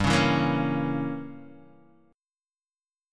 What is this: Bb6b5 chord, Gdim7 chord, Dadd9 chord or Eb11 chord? Gdim7 chord